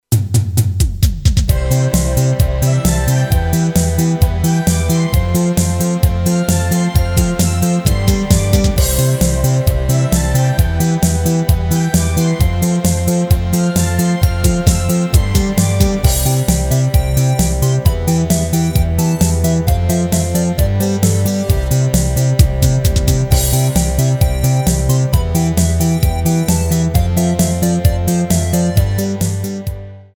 Rubrika: Pop, rock, beat
HUDEBNÍ PODKLADY V AUDIO A VIDEO SOUBORECH